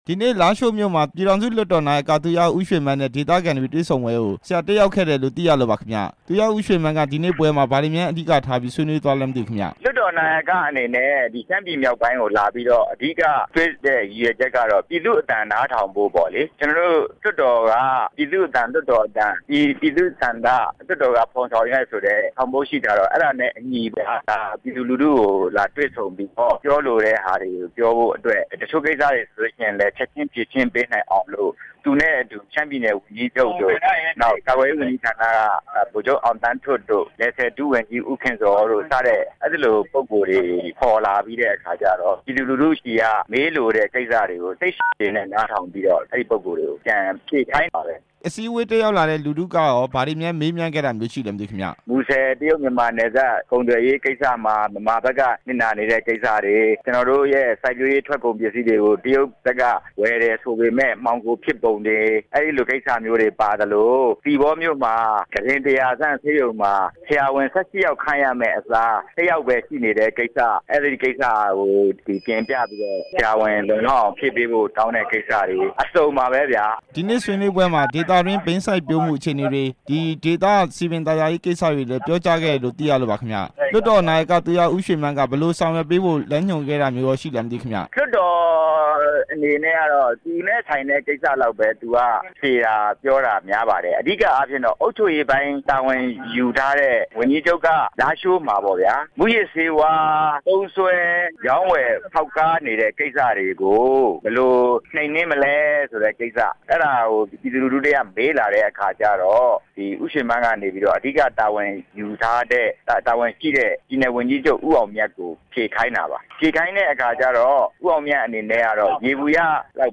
သီပေါ ပြည်သူ့လွှတ်တော်ကိုယ်စားလှယ် ဦးရဲထွန်းနဲ့ မေးမြန်းချက်